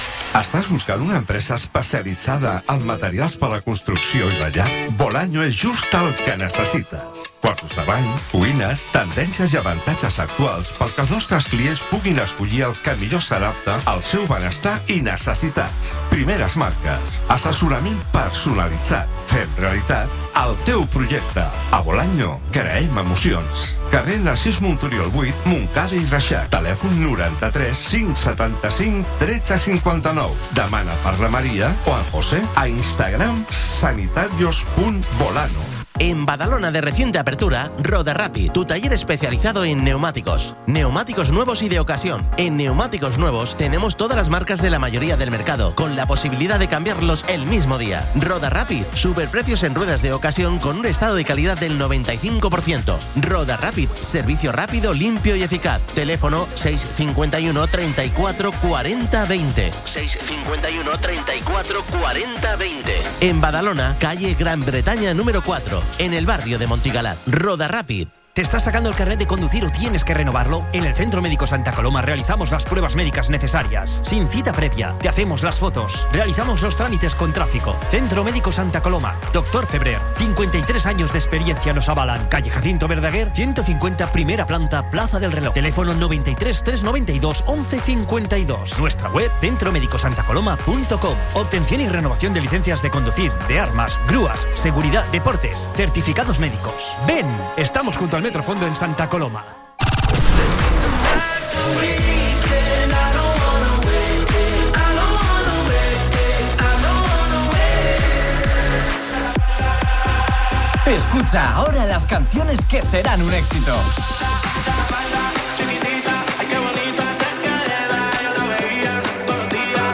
Publicitat, identificació de la ràdio, tema musical